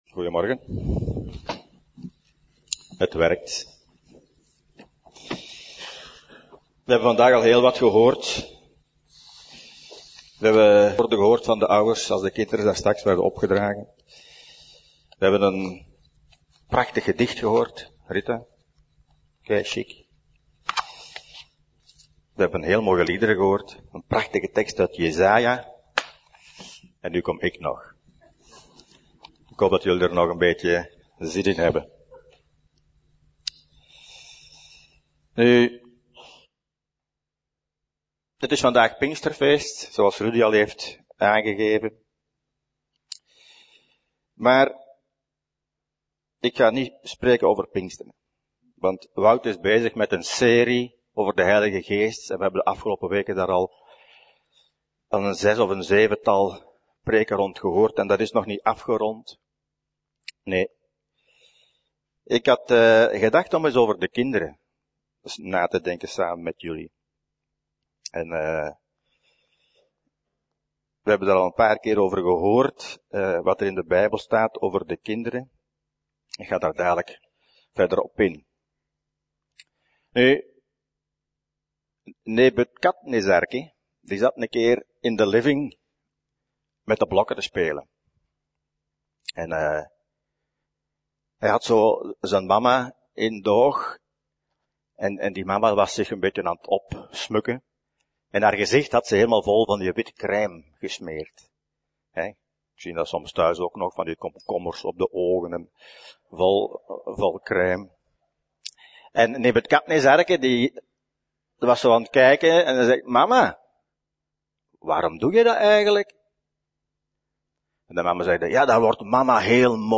Preek: Jezus zegent de kinderen - Levende Hoop